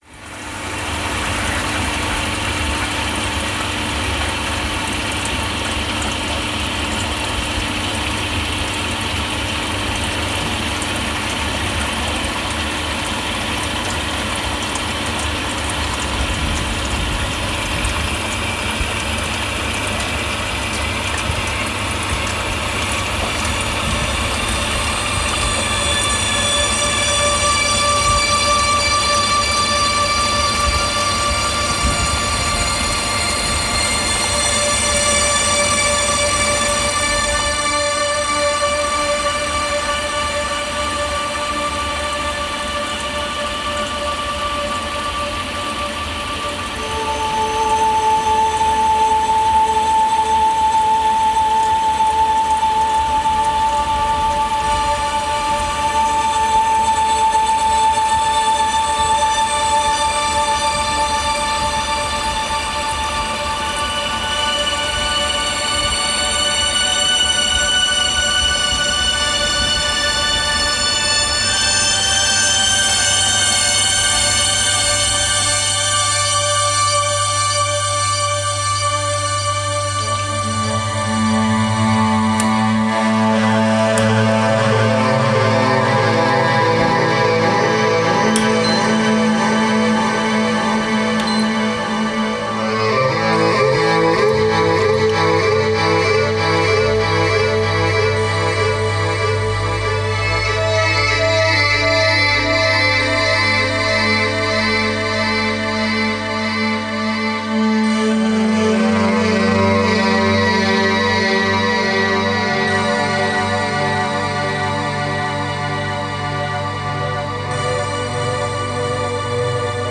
The Washing Machine
Vacuum Cleaner Dirt Devil
The Dishwasher